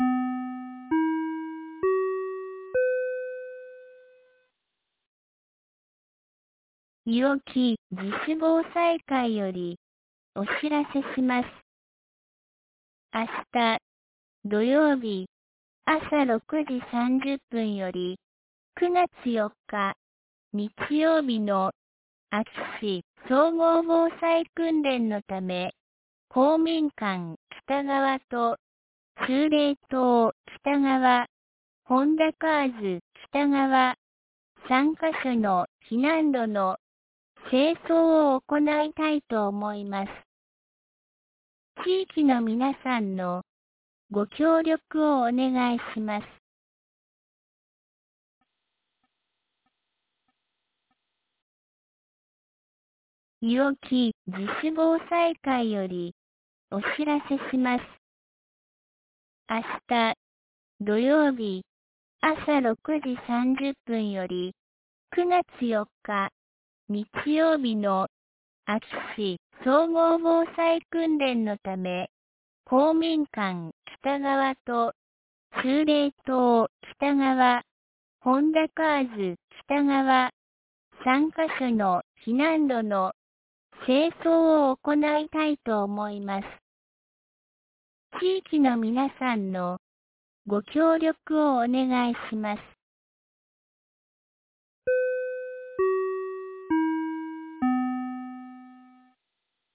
2022年09月02日 17時14分に、安芸市より伊尾木、下山へ放送がありました。